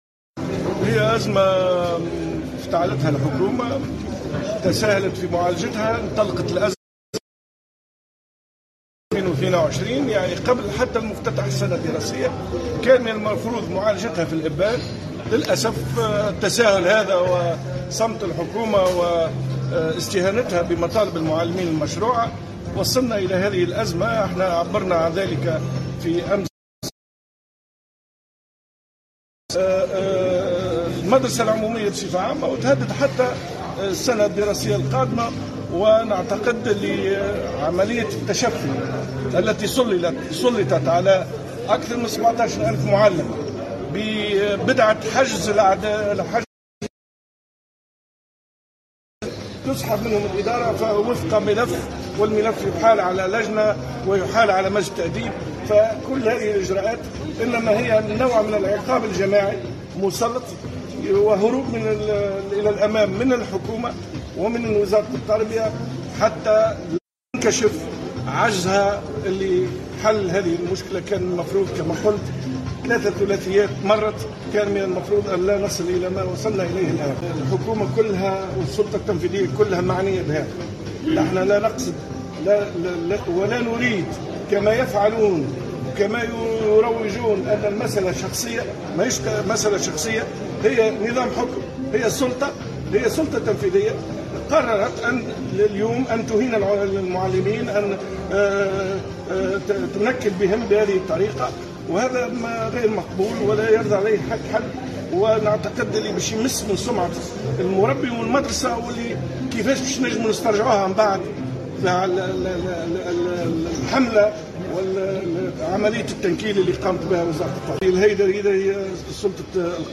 dans une déclaration faite, mercredi,  au journaliste de Tunisie Numérique